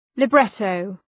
Shkrimi fonetik {lı’bretəʋ}